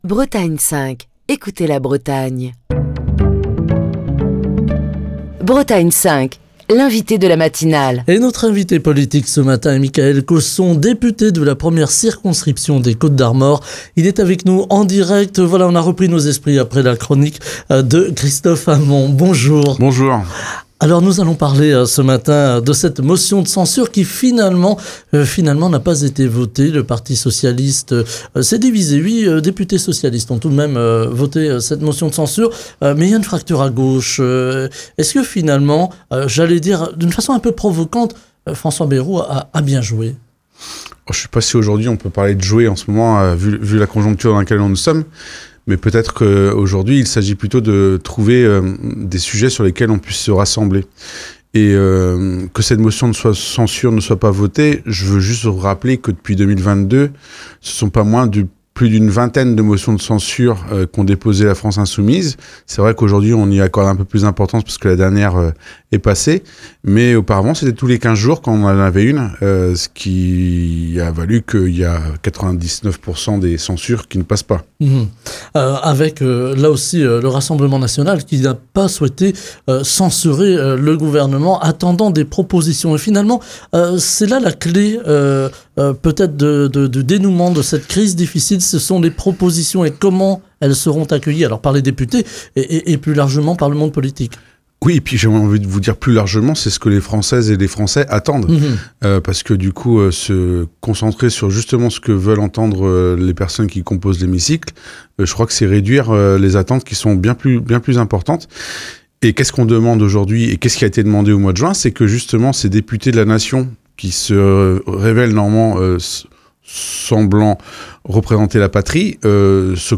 Mickaël Cosson, député de la 1ʳᵉ circonscription des Côtes-d'Armor, était l'invité politique de Bretagne 5 Matin. Le député est revenu sur la motion de censure déposée par La France Insoumise, qui a été rejetée hier par l'Assemblée nationale. Il a exprimé son inquiétude face à l'impasse politique actuelle et a appelé à l’émergence rapide d’un compromis.